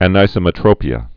(ăn-īsə-mĭ-trōpē-ə)